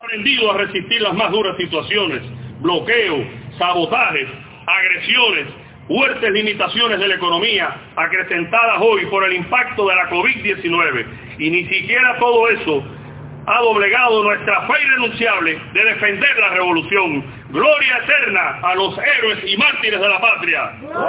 Tributo de recordación a los caídos en el cumplimiento de misiones militares internacionalistas, fue tributado en la mañana de hoy con una gala político-cultural realizada frente al panteón de los caídos por la defensa, en la necrópolis de Bayamo.